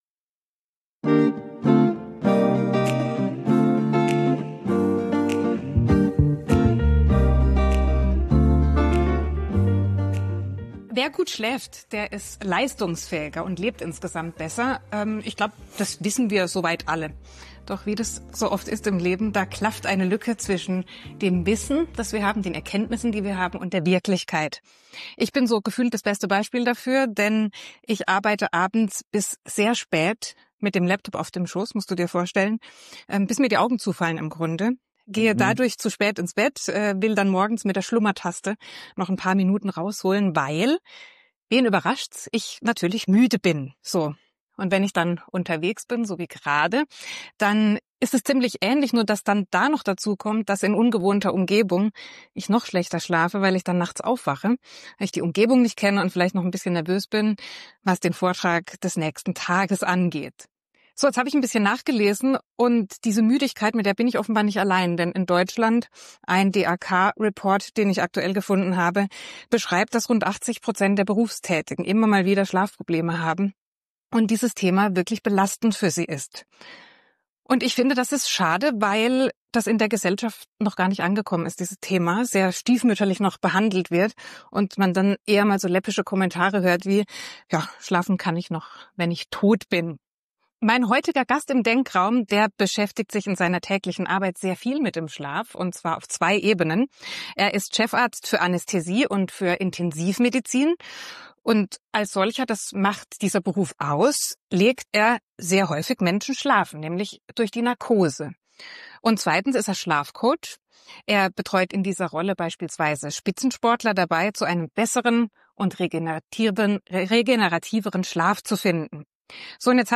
Und warum ein Mittagsschlaf (richtig gemacht!) kein Zeichen von Faulheit ist Dieses Gespräch ist für alle, die besser schlafen, gesünder leben und leistungsfähiger durch den Tag gehen wollen.